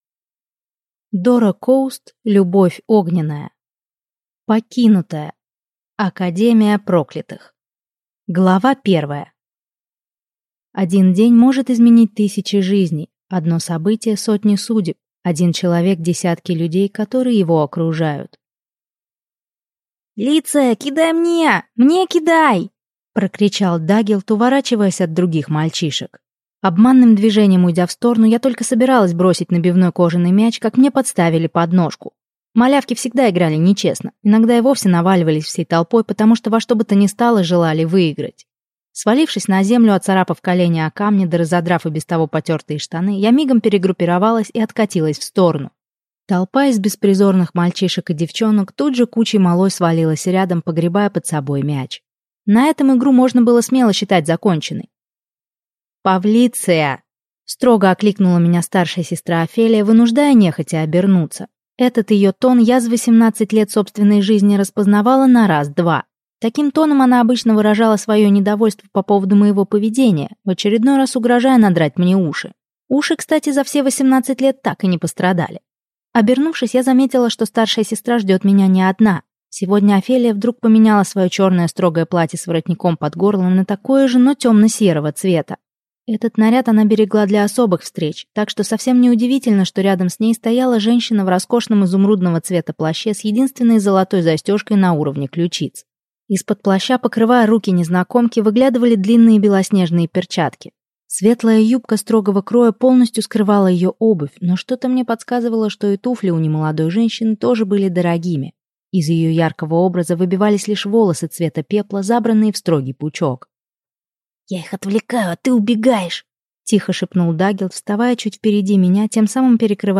Аудиокнига Покинутая. Академия Проклятых | Библиотека аудиокниг